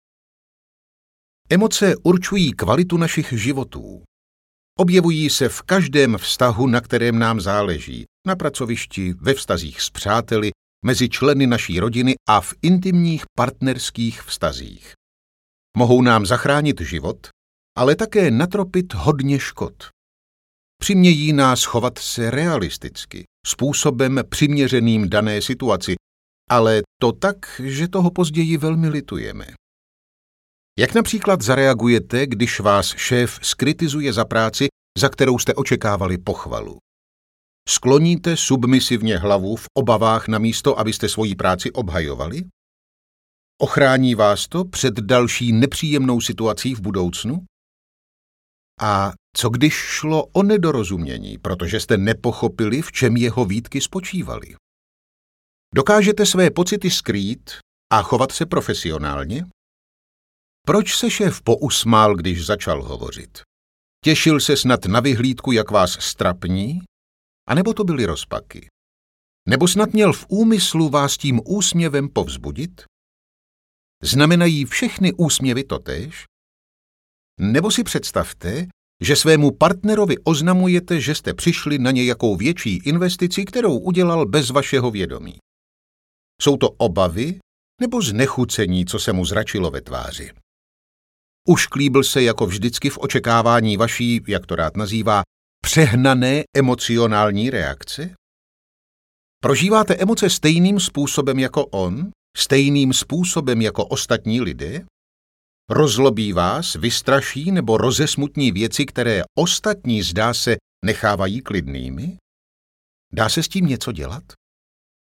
Odhalené emoce audiokniha
Ukázka z knihy